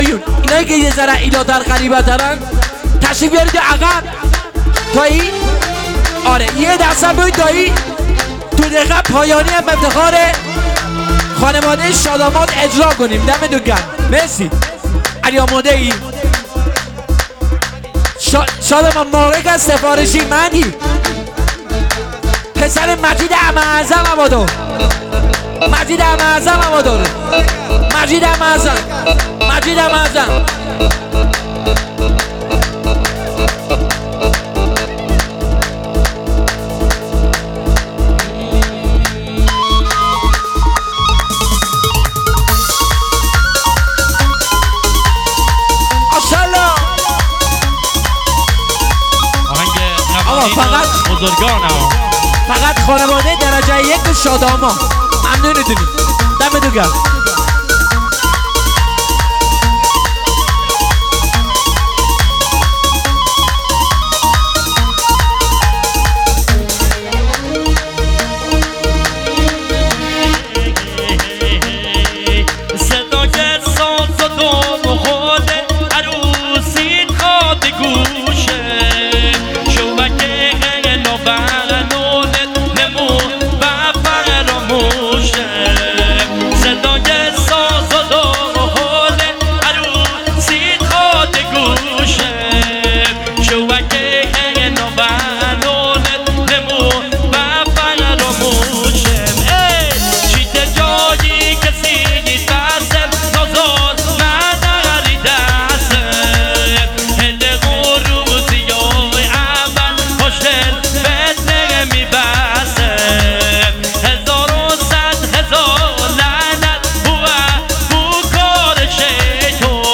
ترانه مجلسی محلی لری